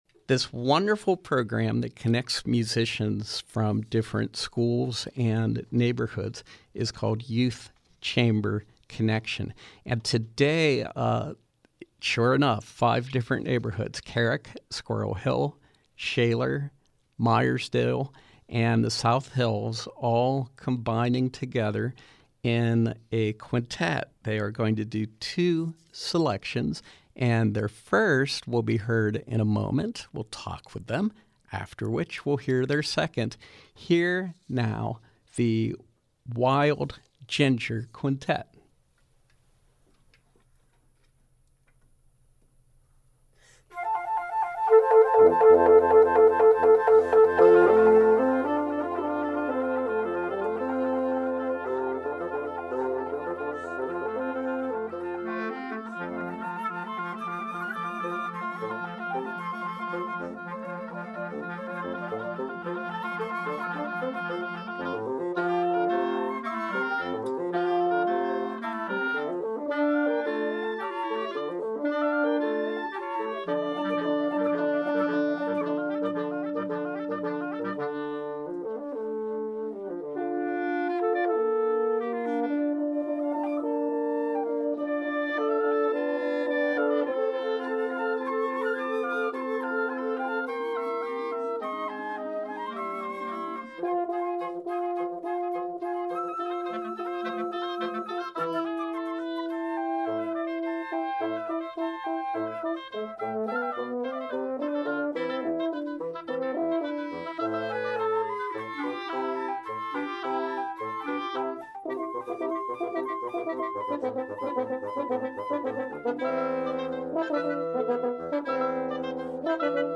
flute
clarinet
french horn
bassoon
oboe